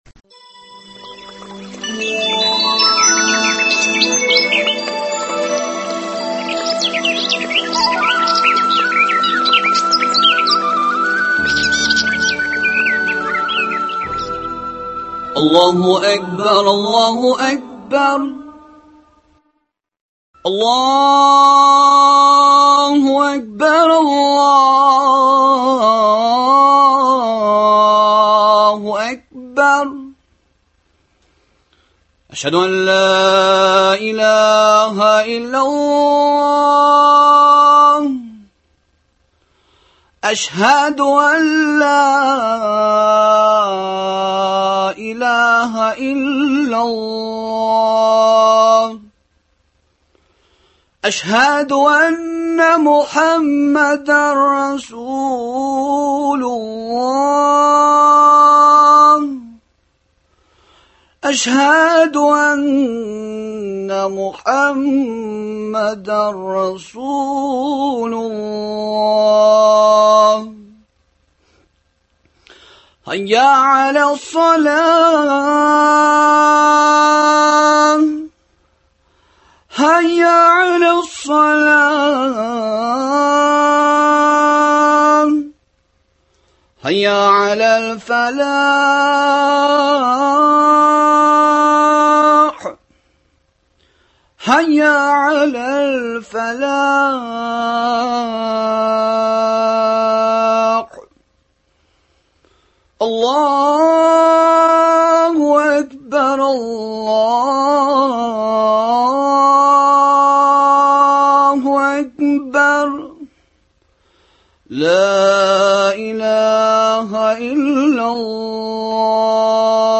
дога кылу тәртипләрен өйрәтү максатында оештырылган әңгәмәләр циклы.